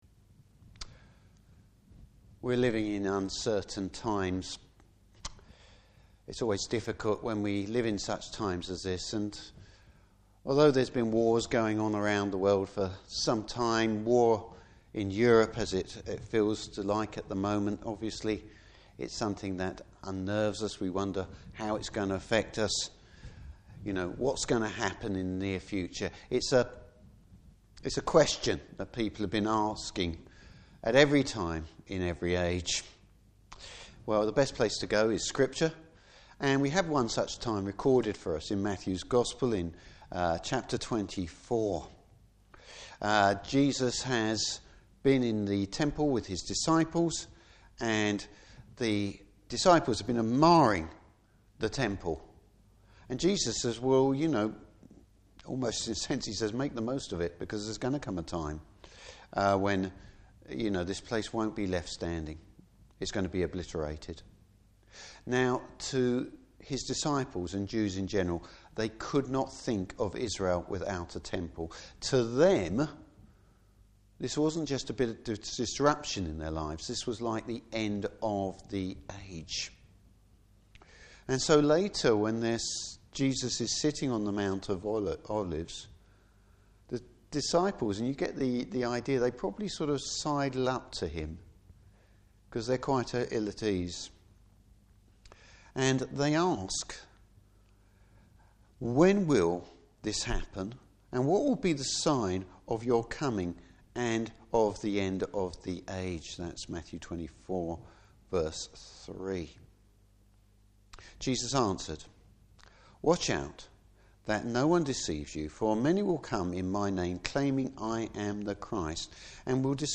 Service Type: Morning Service How should we react and what should do in difficult times?